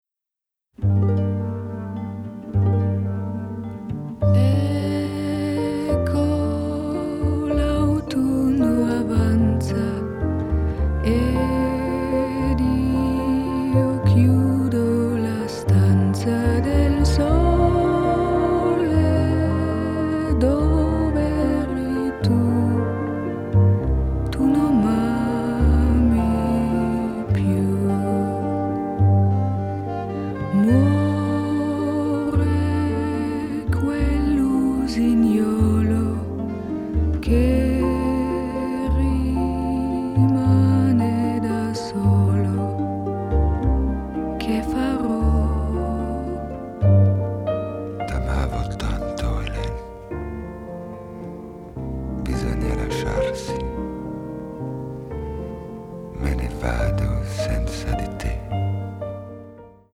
Vocal
Romantic and melancholic